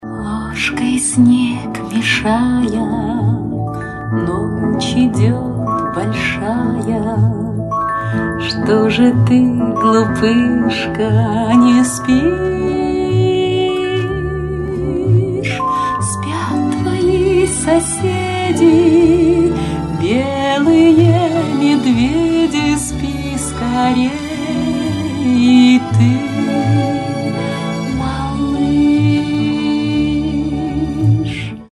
из фильмов